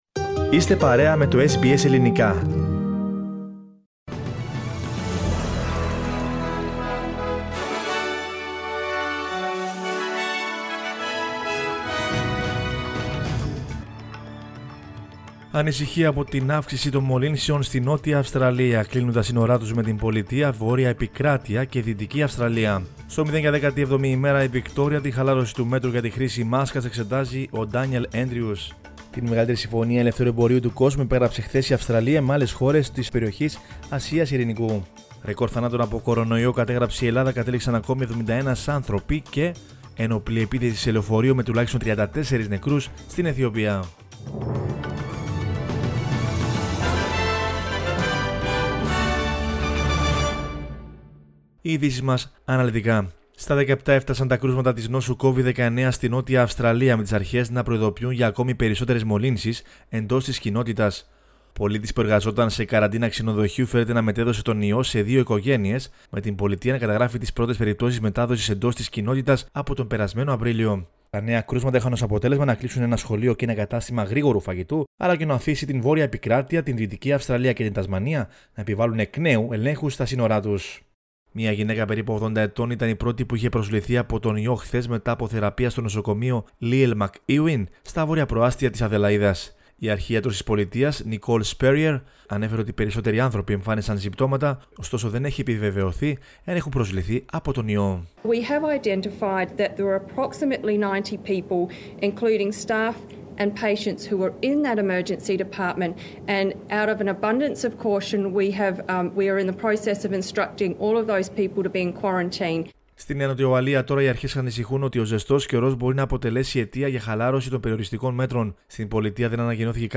News in Greek from Australia, Greece, Cyprus and the world is the news bulletin of Monday 16 November 2020.